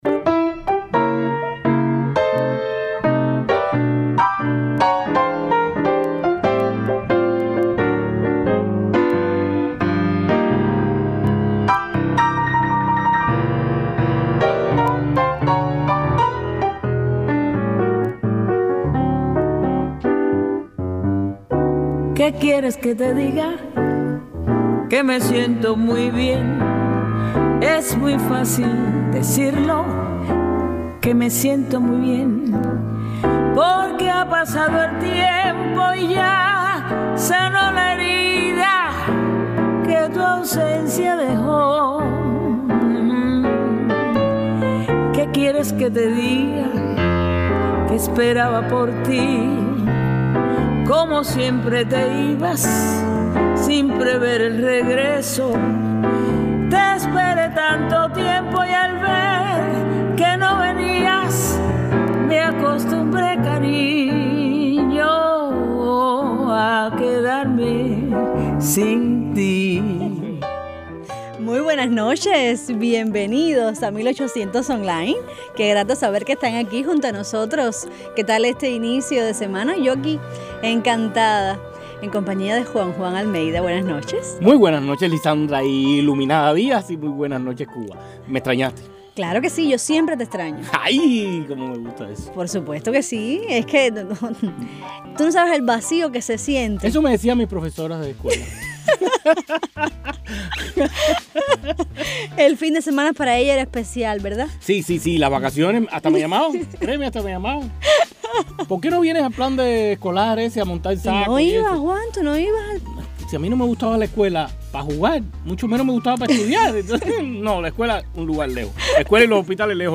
No se pierdan el ritmo cubano dentro de Costa Rica> Conjunto Chocolate!